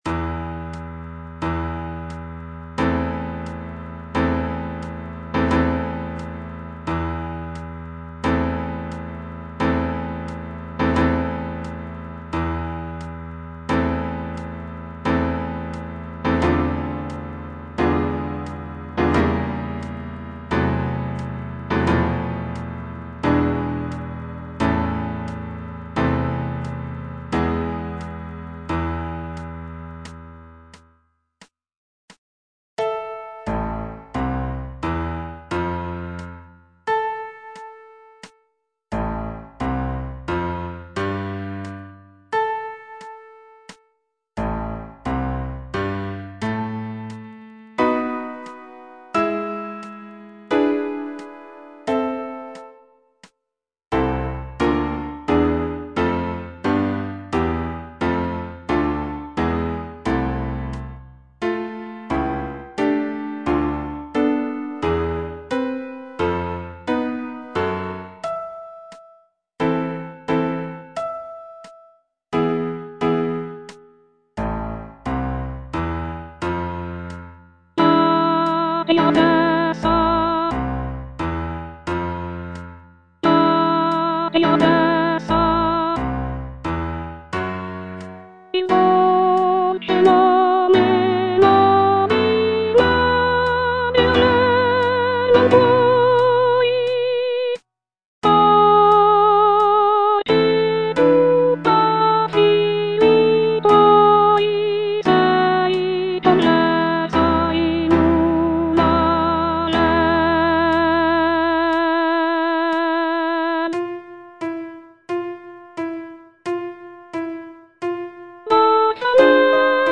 G. VERDI - CORO DI PROFUGHI SCOZZESI FROM "MACBETH" Soprano I (Voice with metronome) Ads stop: auto-stop
The piece features rich harmonies and powerful melodies that evoke a sense of sorrow and longing.